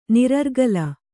♪ nirargala